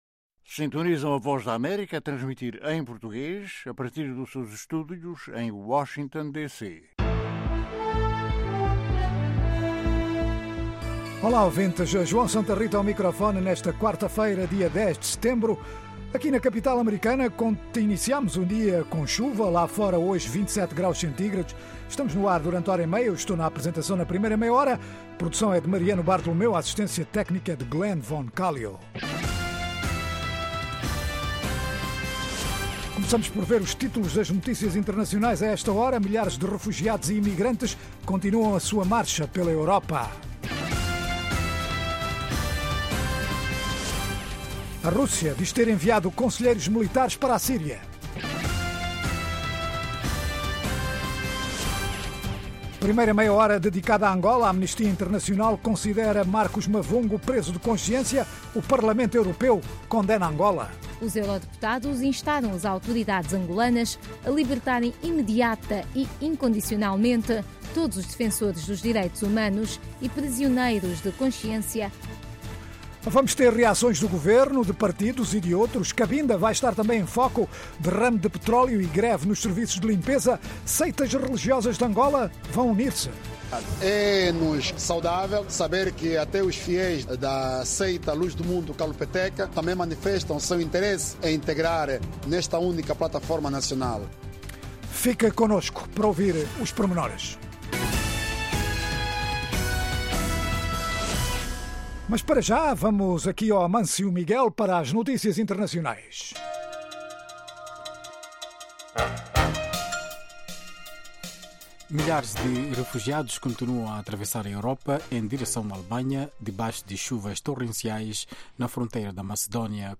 Emissão Vespertina Seg-Qui Um programa orientado aos países Lusófonos de África, sem esquecer o Brasil e comunidades de língua portuguesa noutras partes do mundo. Oferece noticias, informação e analises e divide-se em três meias-horas: 1) Orientado a Angola - com histórias enviadas de Angola, por jornalistas em todo o país sobre os mais variados temas. 2) Notícias em destaque na África lusófona e no mundo, 3) Inclui as noticias mais destacadas do dia, análises, artes e entretenimento, saúde, questões em debate em África.